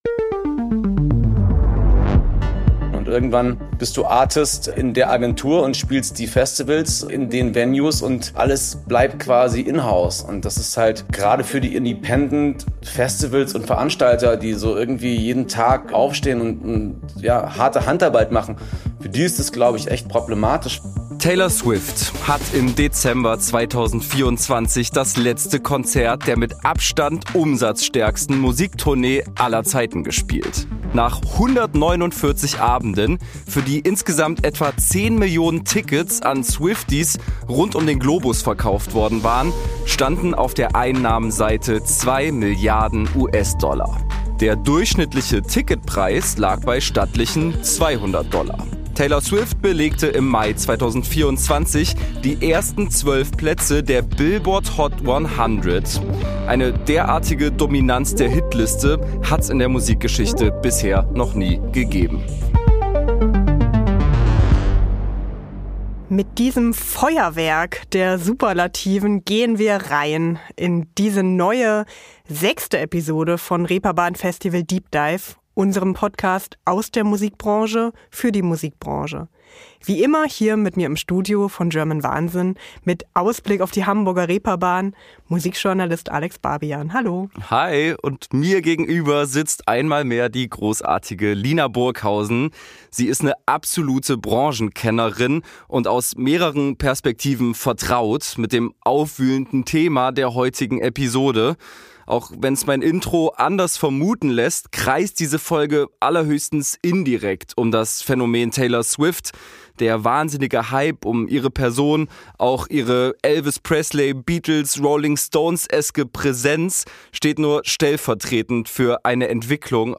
Studio: German Wahnsinn